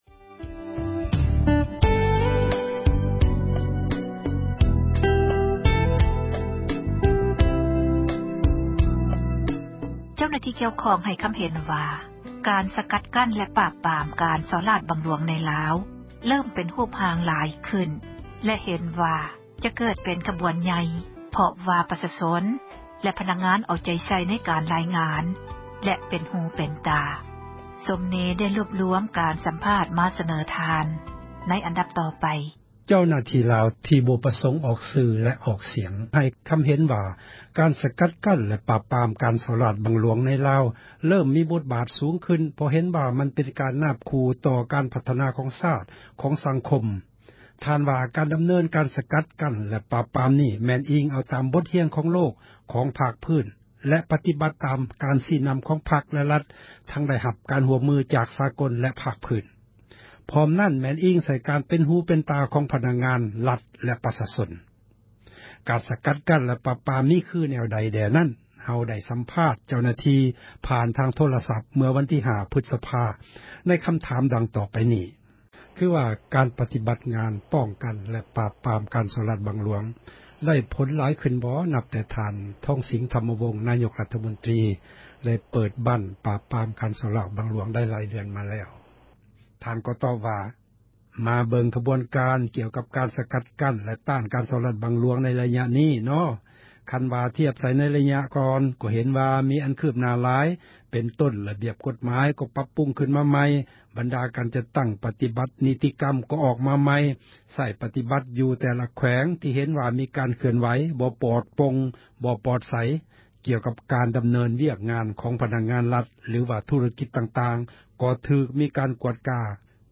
ການ ສະກັດ ແລະ ປາບປາມ ມີຄື ແນວໃດ ແດ່ນັ້ນ ເຮົາໄດ້ ສັມພາດ ເຈົ້າໜ້າທີ່ ຜ່ານ ທາງ ໂທຣະສັບ ເມື່ອ ວັນທີ 5 ພຶສພາ ໃນ ຄໍາຖາມ ດັ່ງ ຕໍ່ໄປນີ້: